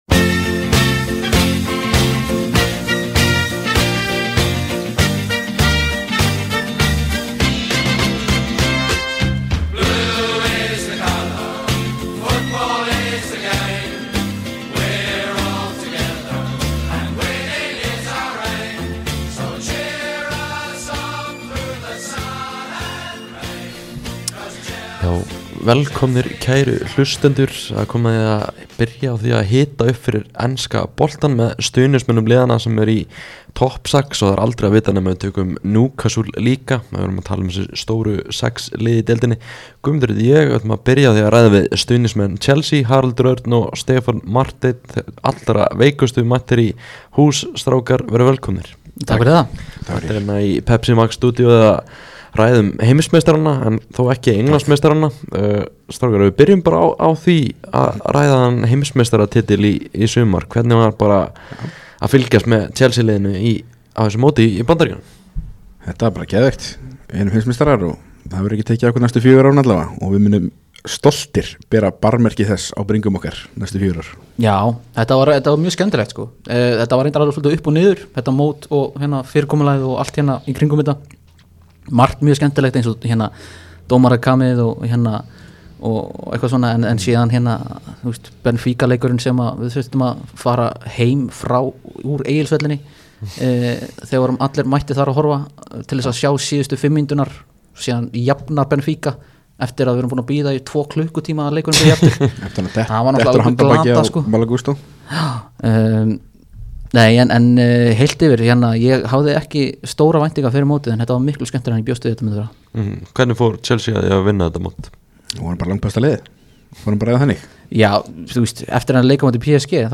þeir komu í heimsókn í Pepsi Max stúdíóið í dag til að fara yfir stöðuna hjá Lundúnafélaginu. Ræddu þeir meðal annars um HM félagsliða, nýja leikmenn, sambandið við Arsenal og margt fleira.